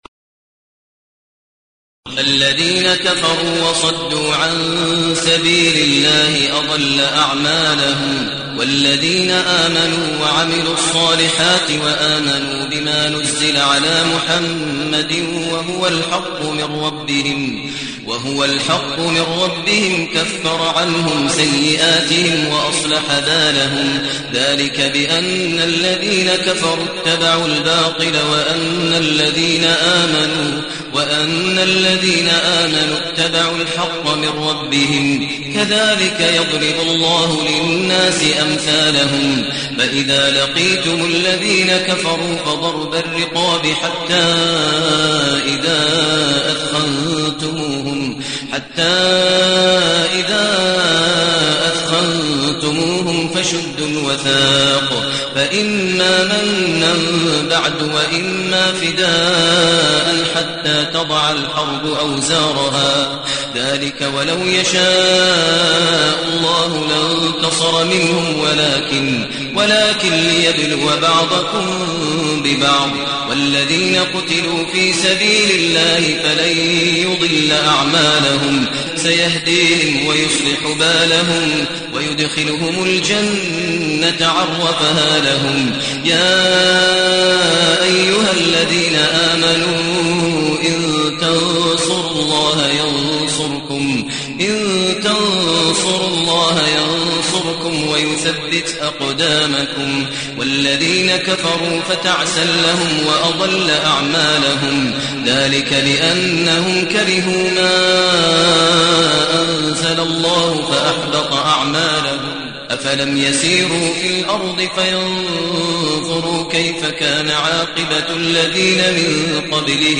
المكان: المسجد النبوي الشيخ: فضيلة الشيخ ماهر المعيقلي فضيلة الشيخ ماهر المعيقلي محمد